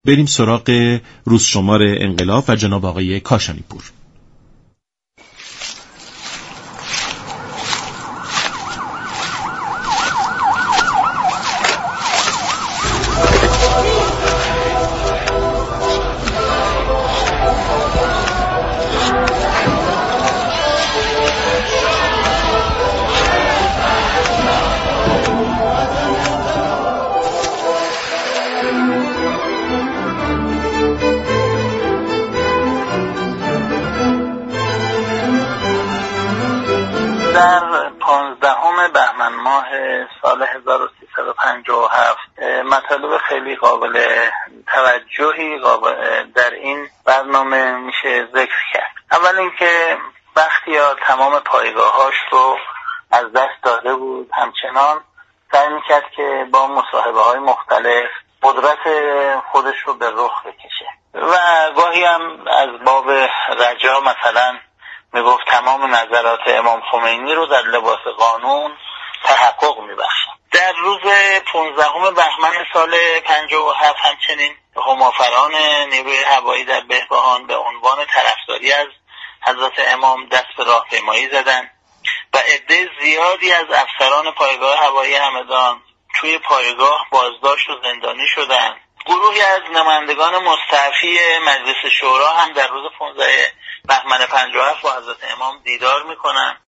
به صورت زنده پخش می شود.